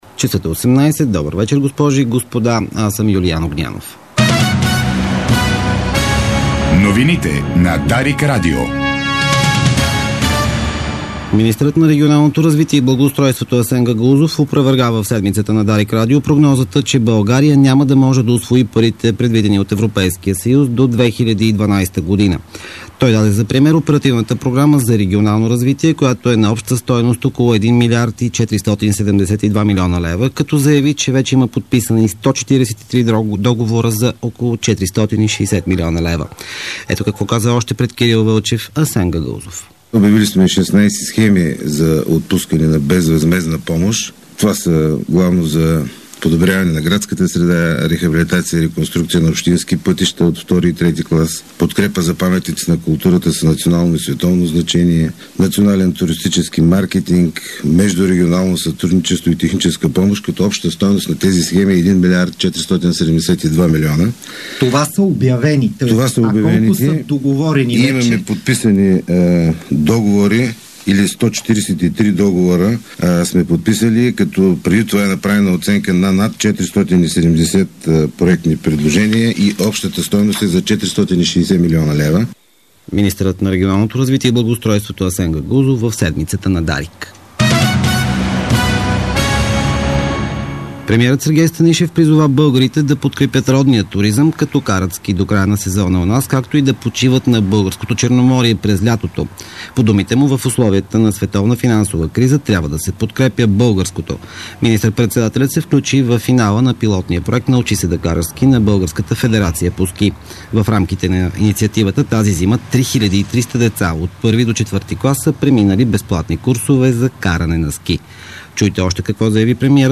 Обзорна информационна емисия - 28.03.2009